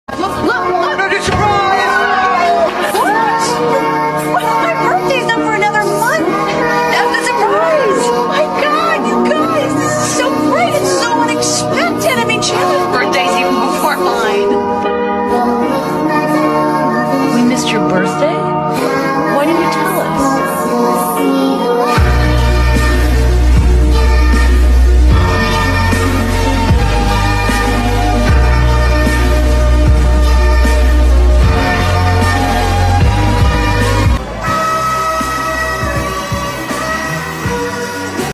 || idk why the sound got all weird.